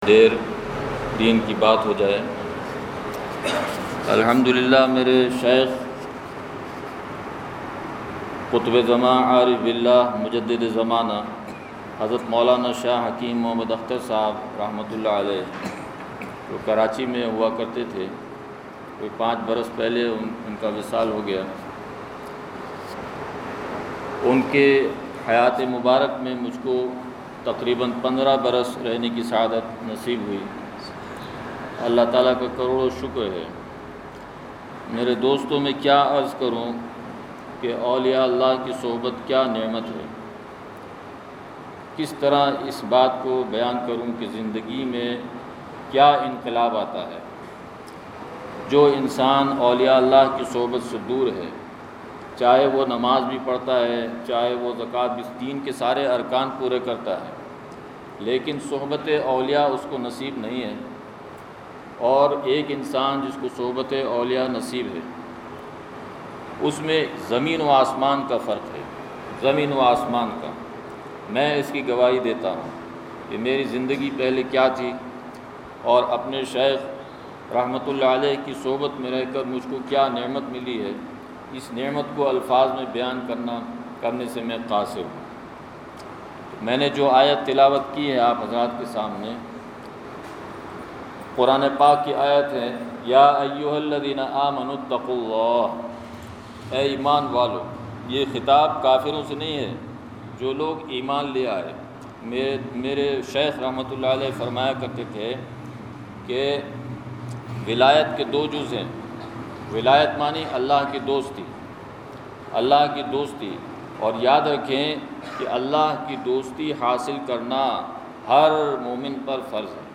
بیان نمبر ۱ – مینگورہ سوات